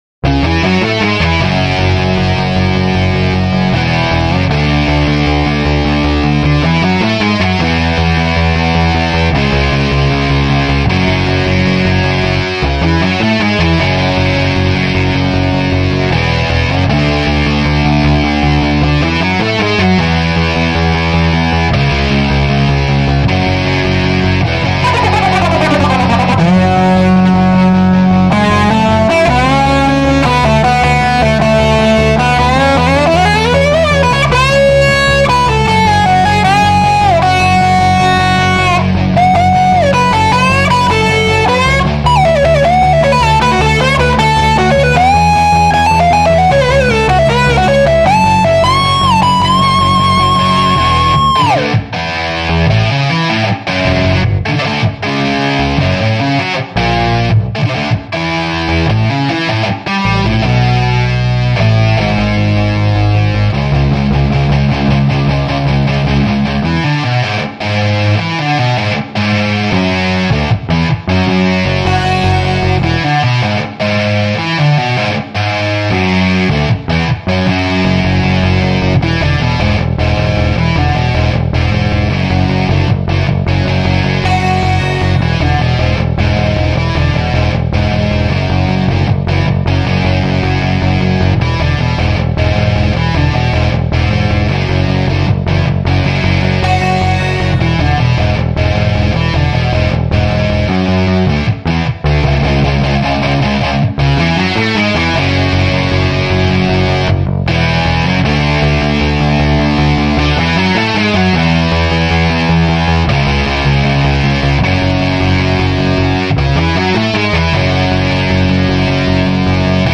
●Rickenbacker●Telecaster●LesPaul